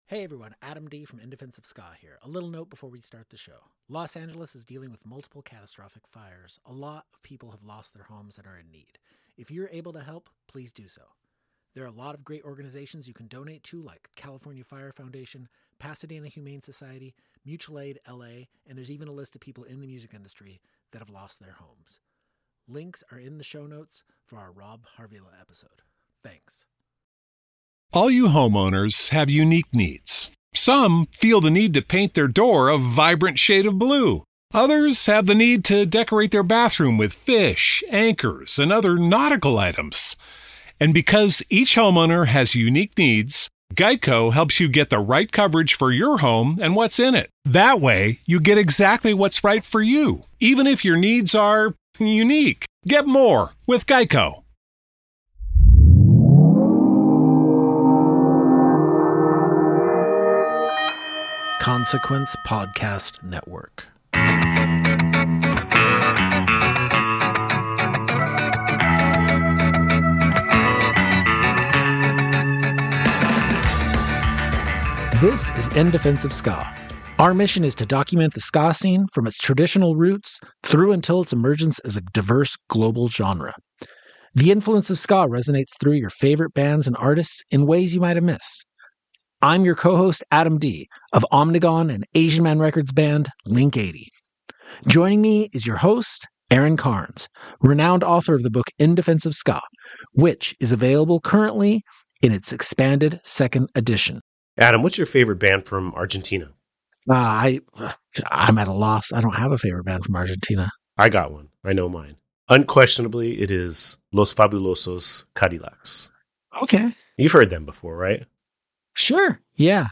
chat with people in and outside of the ska scene to tell its stories, show its pervasiveness in culture, and defend it to their last dying breath.